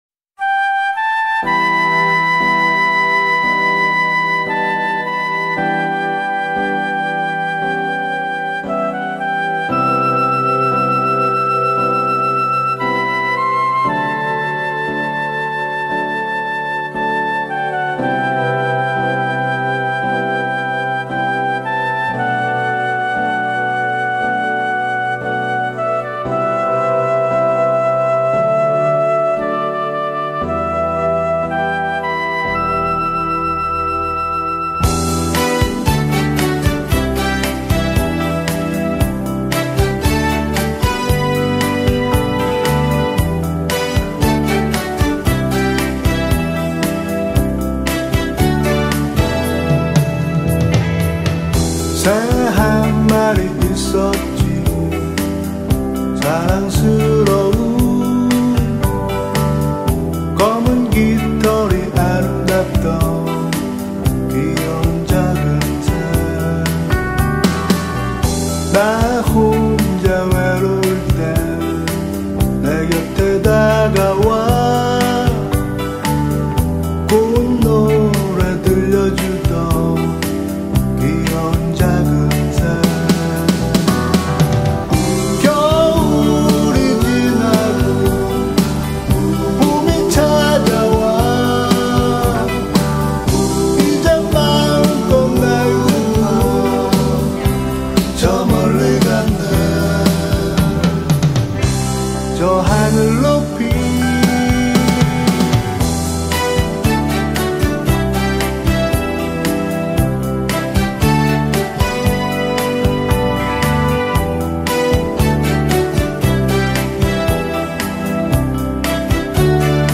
Guitars
Piano & Synthesizers
Bass
Drum & Percussions
Chorus